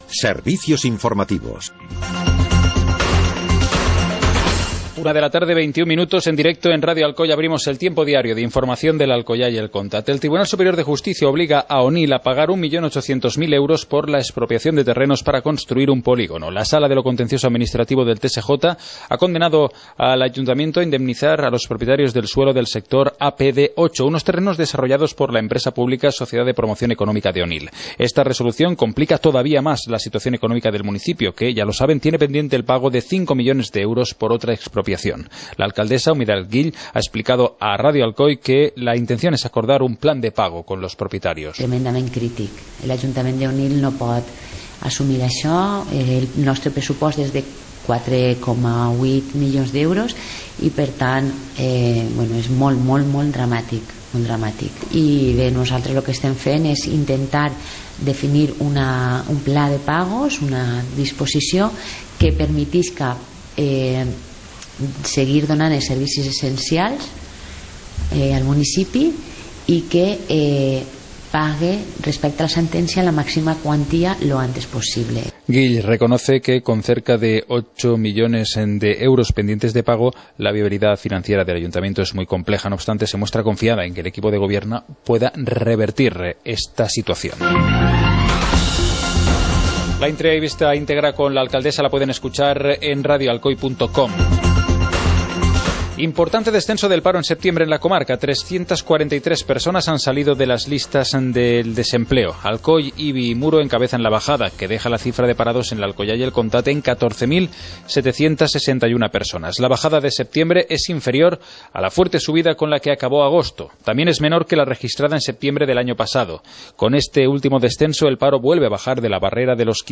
Informativo comarcal - jueves, 08 de octubre de 2015